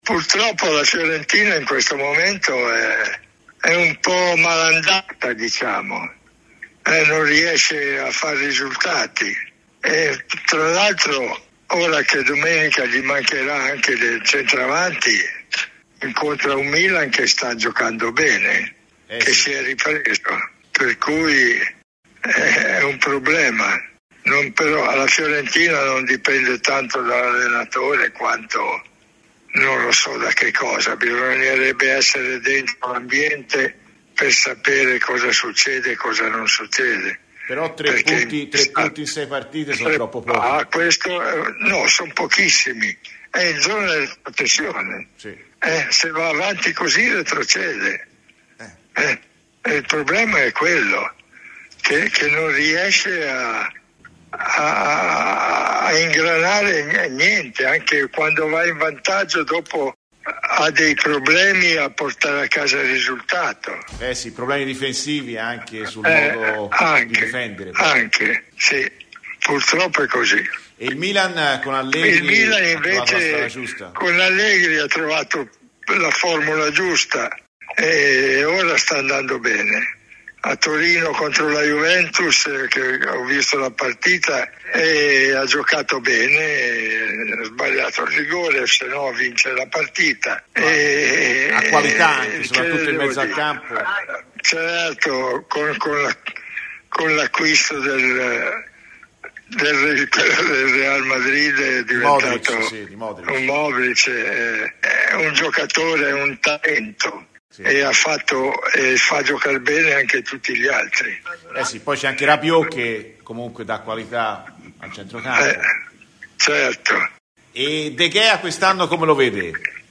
In vista della sfida di domenica a San Siro tra Milan e Fiorentina, Radio FirenzeViola ha raccolto le impressioni di Enrico “Ricky” Albertosi, uno dei portieri più rappresentativi della storia del calcio italiano.